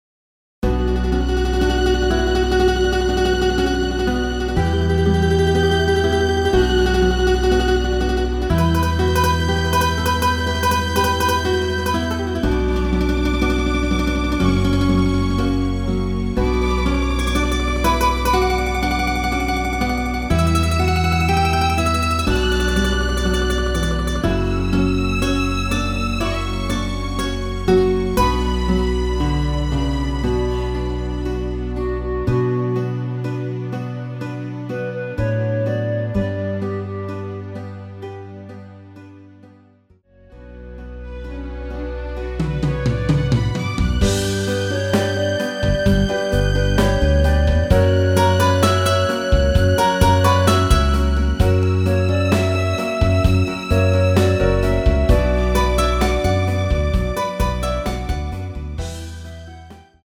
원키에서(+1)올린 멜로디 포함된 MR입니다.
Bm
앞부분30초, 뒷부분30초씩 편집해서 올려 드리고 있습니다.
중간에 음이 끈어지고 다시 나오는 이유는